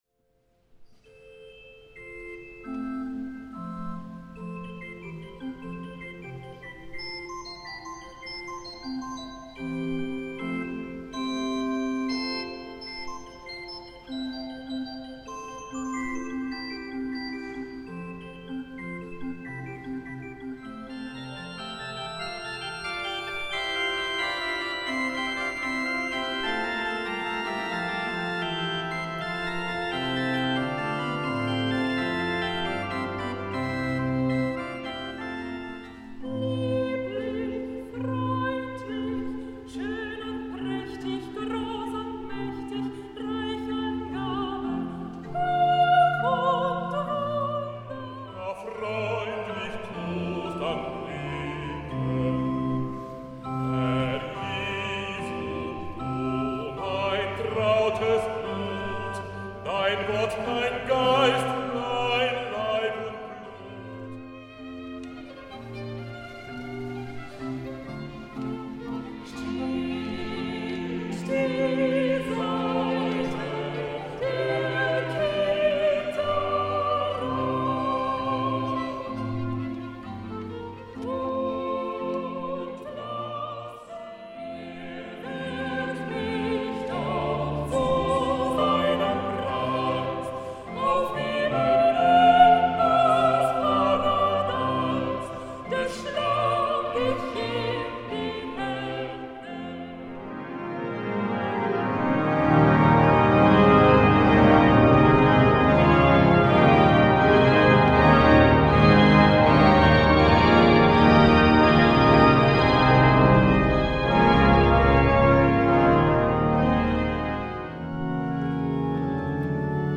Sonnstag, 1.02.2015, 17:00 Uhr, St. Georg, Ulm